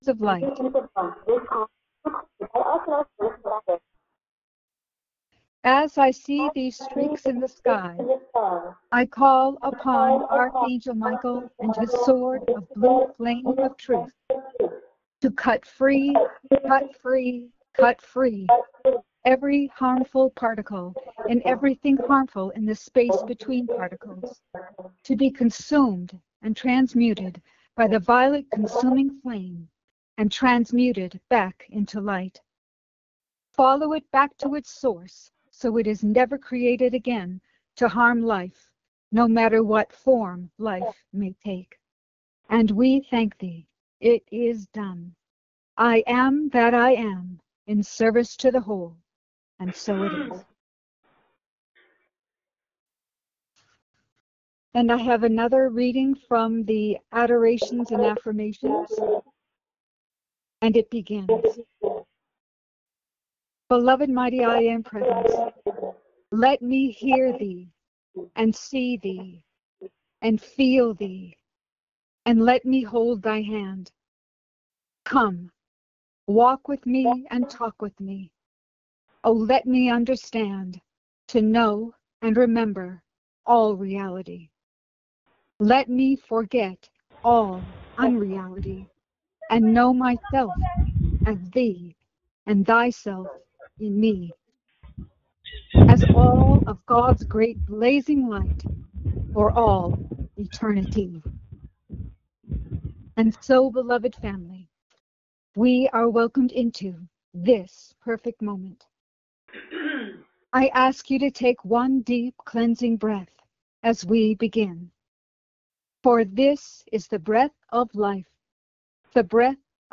Audio Recording Meditation
Channeling – Minute (00:00) Transcription coming soon …. Questions & Answers – Minute (00:00)